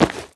foley_object_grab_pickup_rough_04.wav